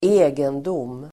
Uttal: [²'e:gendom:]